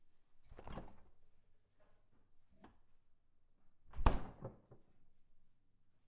SFX库类项目VS " 冰箱打开关闭两次
描述：冰箱开合两次